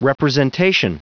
Prononciation du mot representation en anglais (fichier audio)
Prononciation du mot : representation